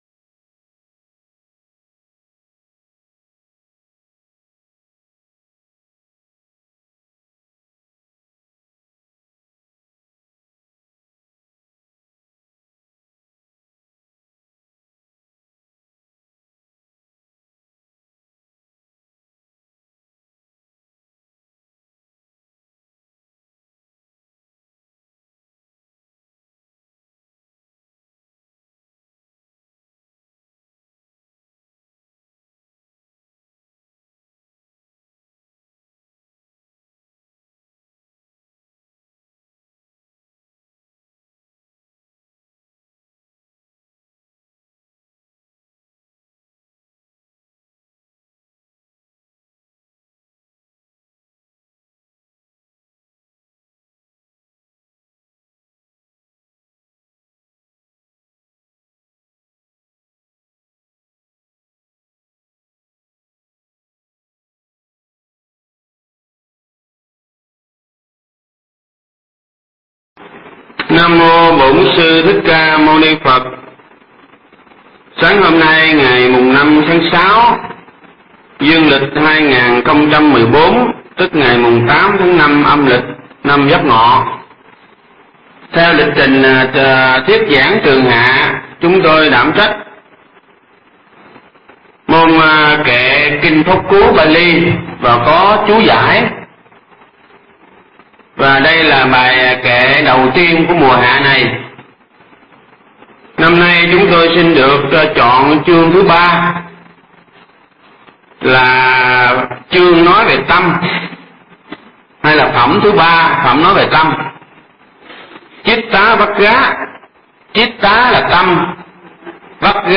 Nghe Mp3 thuyết pháp Kinh Pháp Cú 33
Tải mp3 pháp thoại Kinh Pháp Cú 33